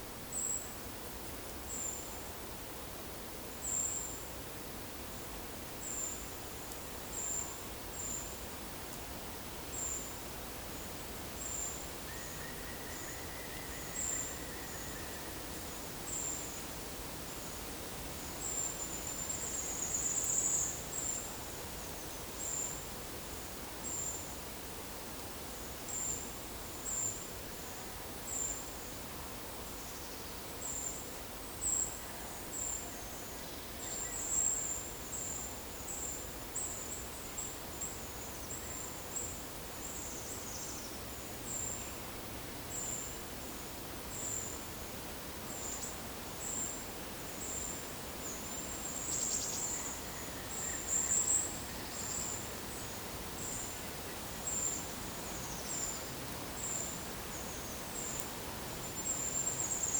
Monitor PAM
Lophophanes cristatus
Regulus ignicapilla
Certhia brachydactyla
Certhia familiaris